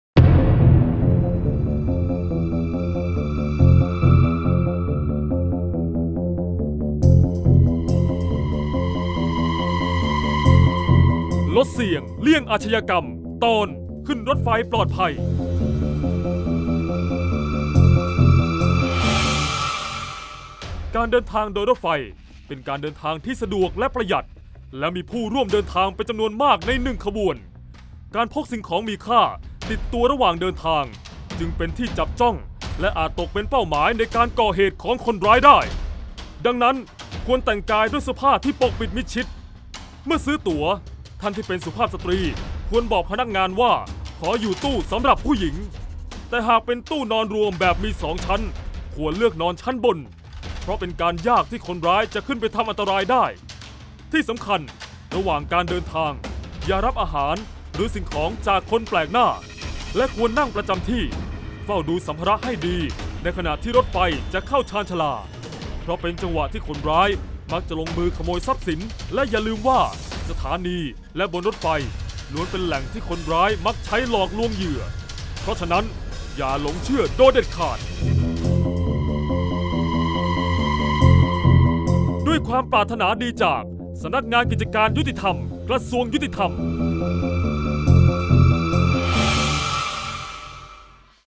เสียงบรรยาย ลดเสี่ยงเลี่ยงอาชญากรรม 22-ขึ้นรถไฟปลอดภัย